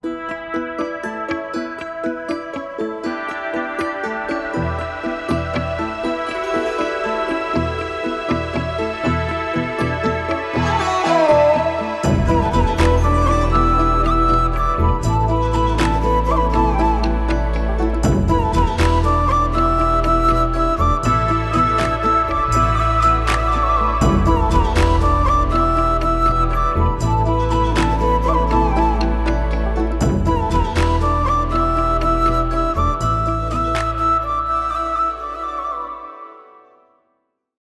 without dialogues and unwanted sounds